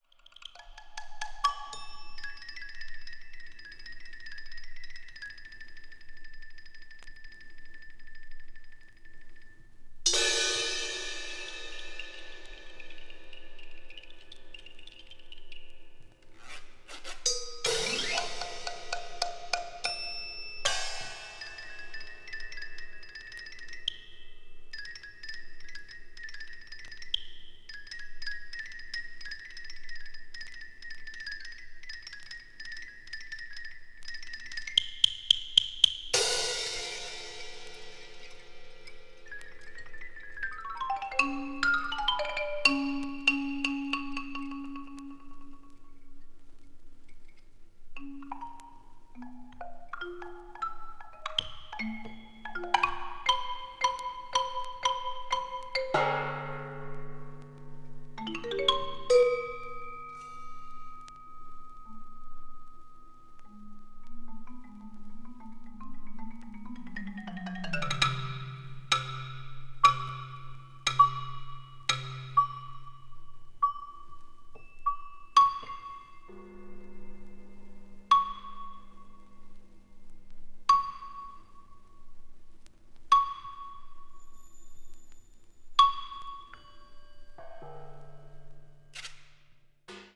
media : EX/EX(わずかにチリノイズが入る箇所あり)
20th century   contemporary   percussion solo   post modern